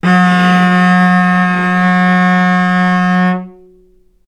vc-F#3-ff.AIF